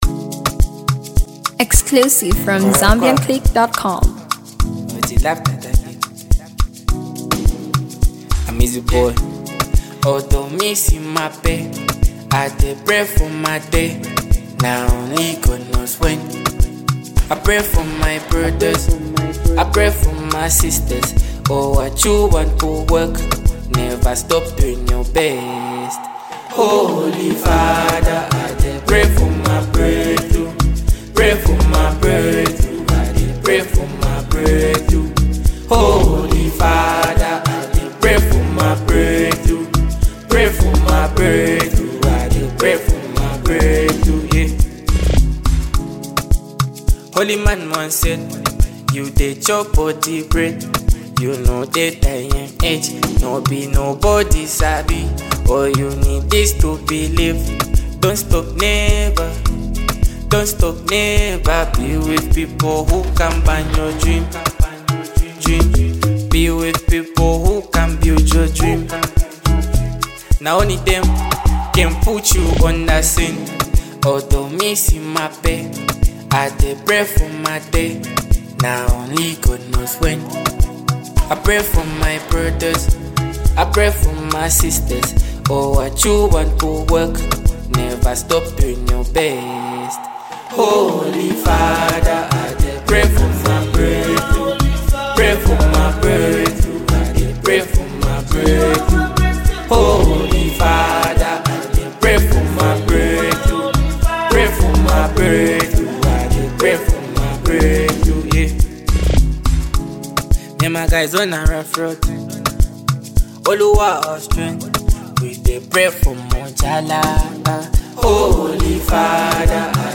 Zambian indigenous singer